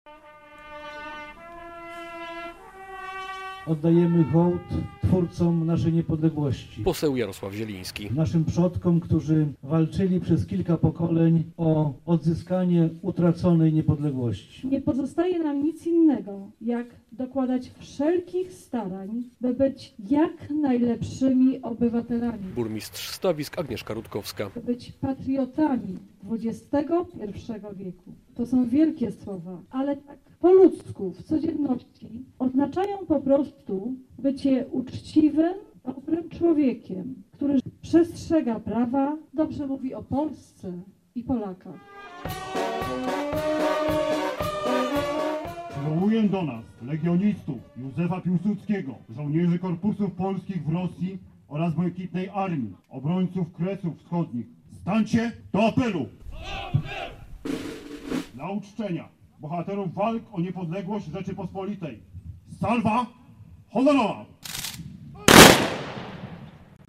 W uroczystości pod pomnikiem Niepodległej Ojczyzny wzięli udział żołnierze, mieszkańcy gminy, a także przedstawiciele samorządów i parlamentu.
Musimy być patriotami XXI wieku - mówiła podczas gminnych obchodów Święta Niepodległości burmistrz Stawisk, Agnieszka Rutkowska.
Głos zabrał też poseł Jarosław Zieliński.